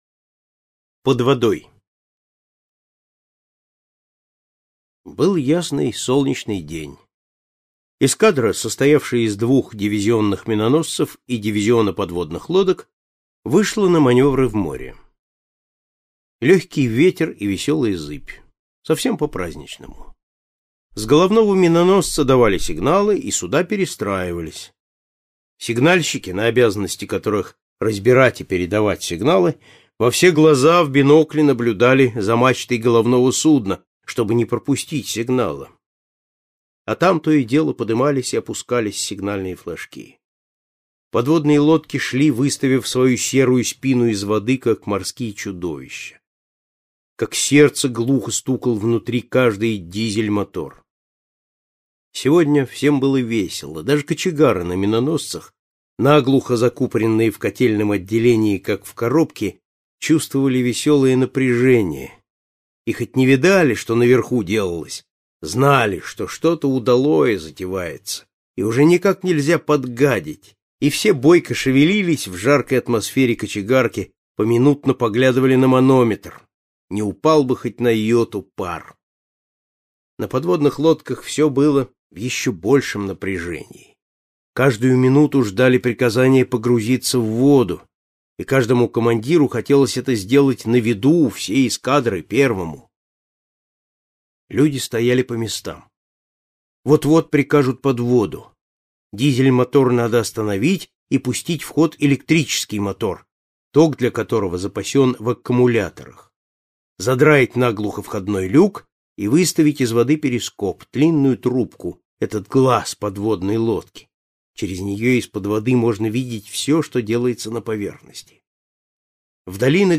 Под водой - аудио рассказ Житкова - слушать онлайн